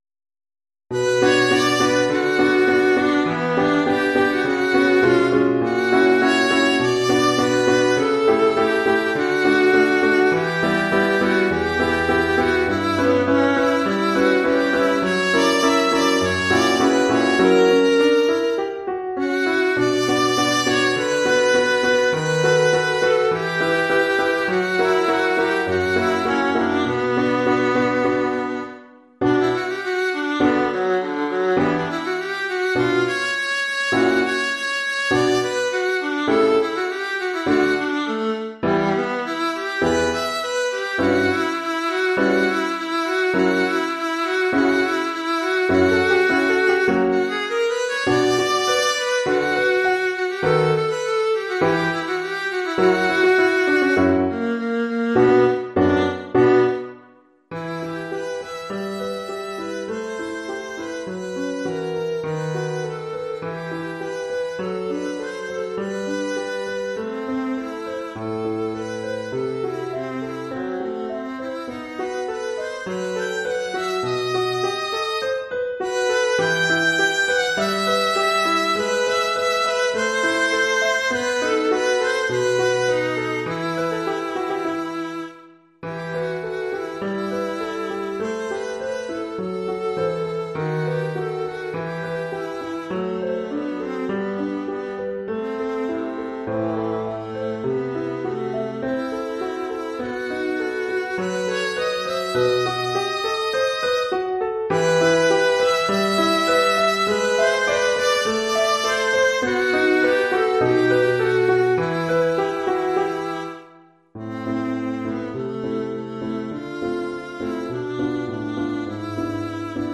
Oeuvre pour alto et piano.
Sous-titre : "Variations pour alto
avec accompagnement de piano".